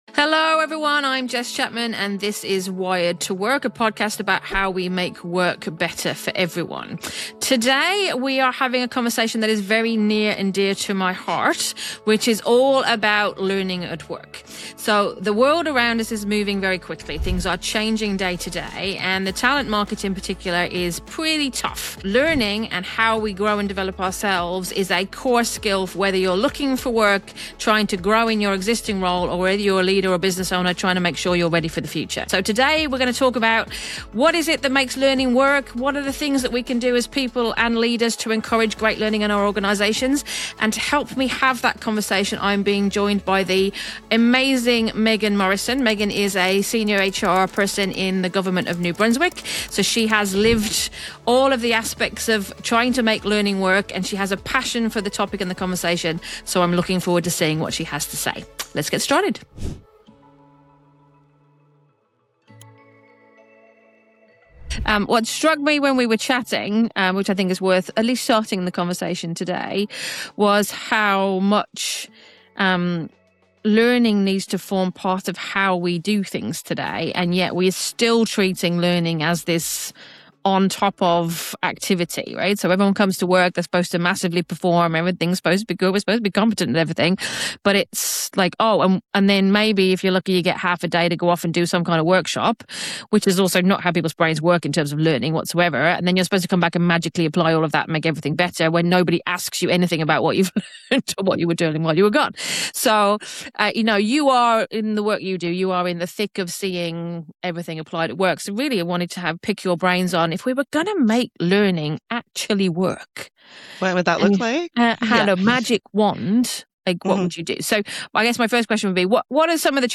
Why Workplace Training FAILS : A Conversation